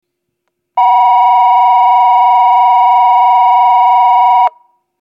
１番線発車ベル
（長野より）   長野より（改札口付近）の電子電鈴での収録です。